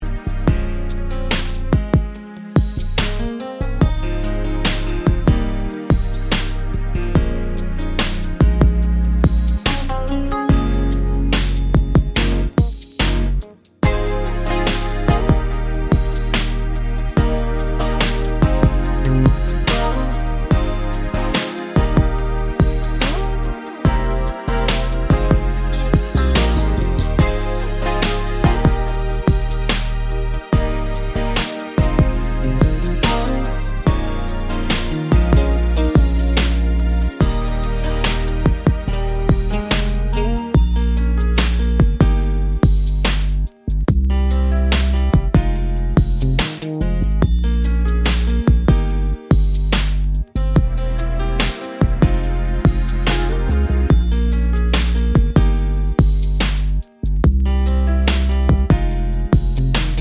no Backing Vocals Soul / Motown 4:20 Buy £1.50